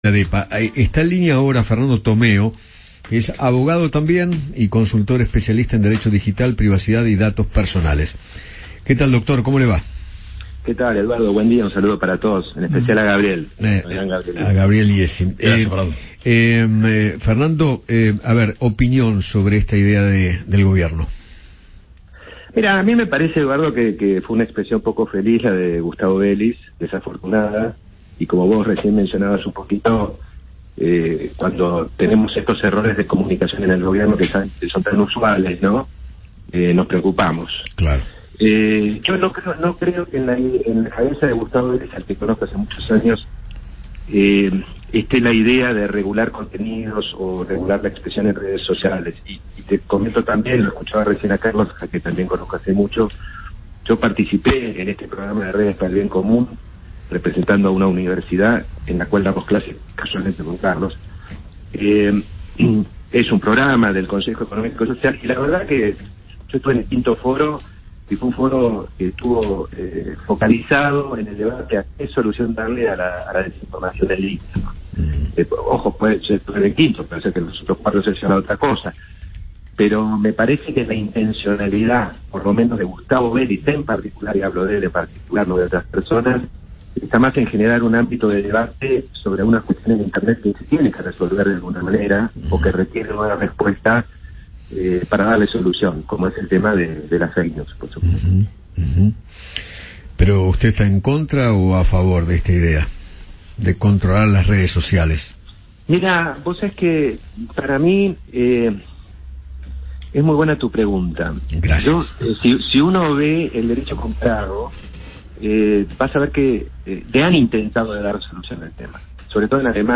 (Audio de Gustavo Bélz)